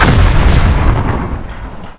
Expl10.wav